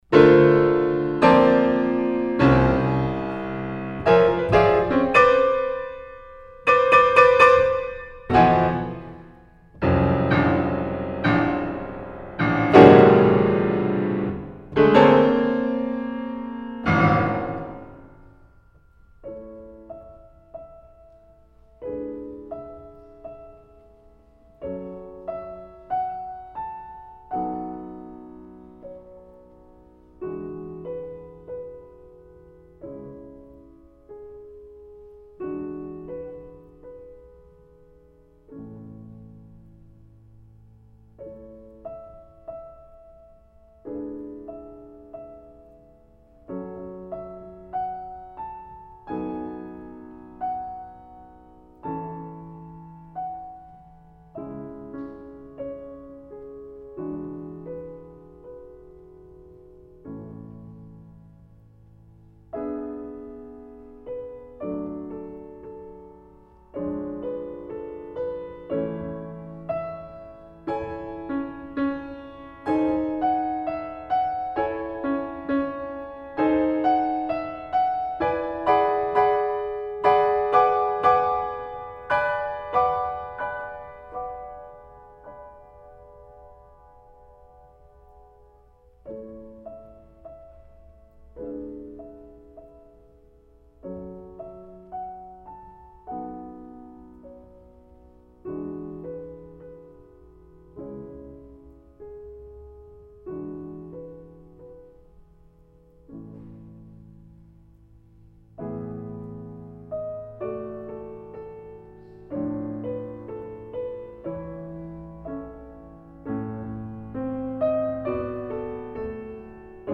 I thought I had lost the printed program from that event but I finally found it, and it appears below, along with a number of audio excerpts from the concert.
Even as the concert was in progress I winced a bit when arriving at Carl Nielsen’s The Jumping Jack, a slight, silly piece that ended up on the program solely for my inability to find anything more interesting by a composer whose named started with N.
The L-S sequence of pieces perhaps best illustrates how the rapid fire succession of pieces causes the series to become a work in and of itself.